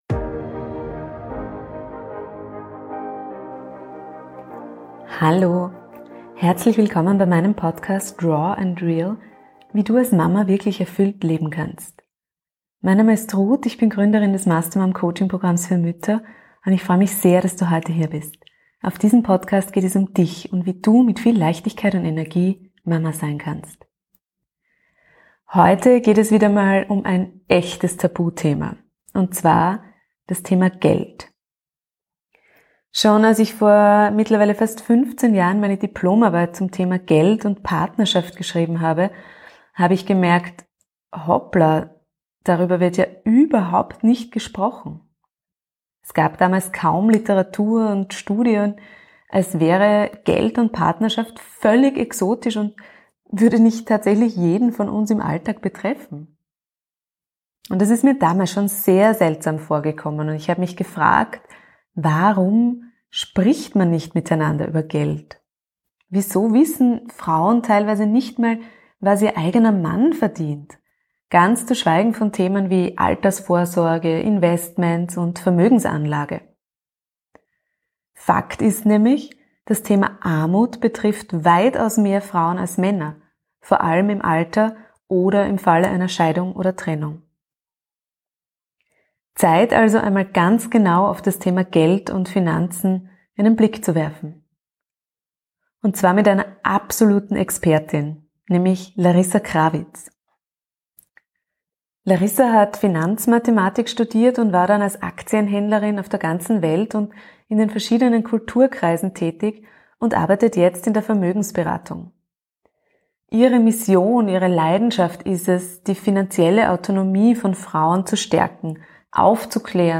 #88 Tabuthema Geld: Frauen und ihre Finanzen. Interview